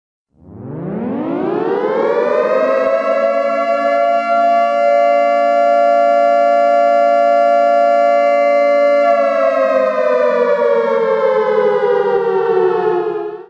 Nuke Sound Button - Free Download & Play
Reactions Soundboard1,892 views